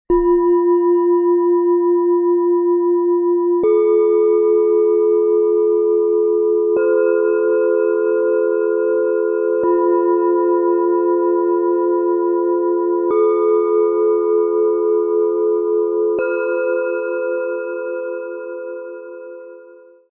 Gelassenheit & Licht - Klangreise nach oben - Set aus 3 Klangschalen, Ø 11,6 - 14,1 cm, 1,47 kg
Die größte Schale klingt tief, weich und voll.
Die mittlere Schale bringt Helligkeit und freundliche Harmonie in die Mitte.
Die kleinste Schale klingt fein und licht - spielerisch und leicht.
Ein tiefer, tragender Klang, der Stabilität und innere Ruhe vermittelt.
Ein klarer, heller Klang, der Herz und Mitte anspricht.
Ein feiner, verspielter Ton, der nach oben öffnet und ein Gefühl von Leichtigkeit und Klarheit hinterlässt.
Die Klänge entfalten sich sanft - von tiefer Ruhe bis zu feiner Leichtigkeit.
Er bringt die Schalen harmonisch zum Schwingen - weich, rund und ausgewogen.